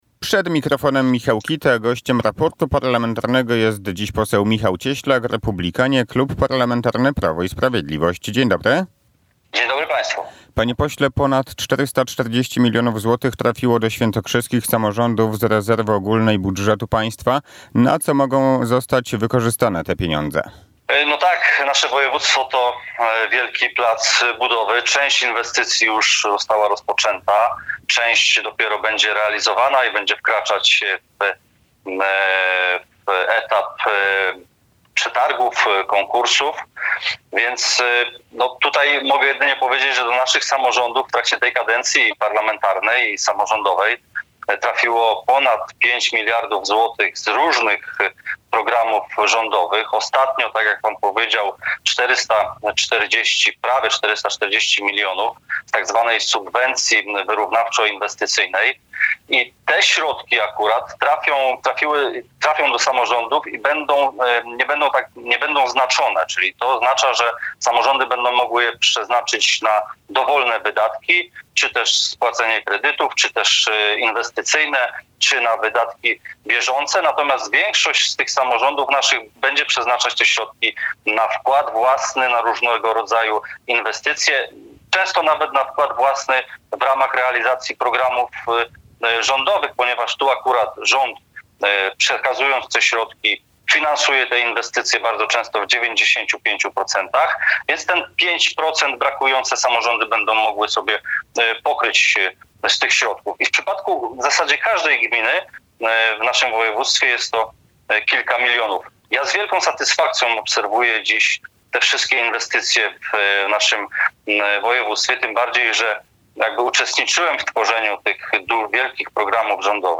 – Województwo świętokrzyskie to wielki plac budowy, w realizacji wielu inwestycji pomaga wsparcie z budżetu państwa.